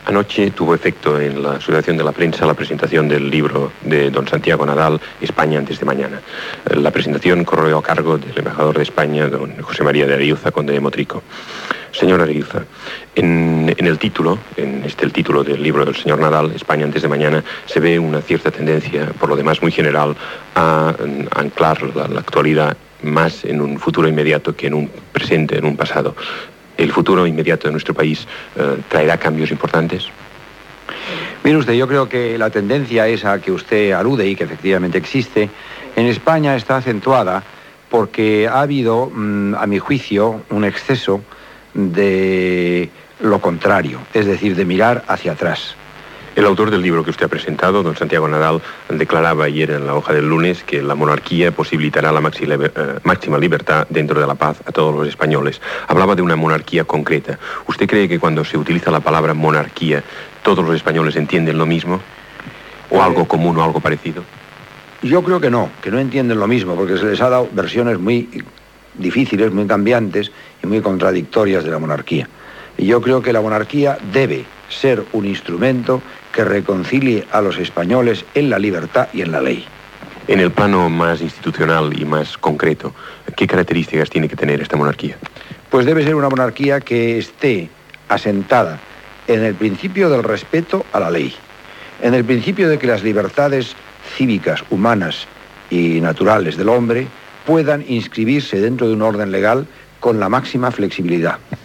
Entrevista a José María de Areilza, ambaixador, que ha fet la presentació del llibre "España antes de mañana" escrit per Santiago Nadal
Informatiu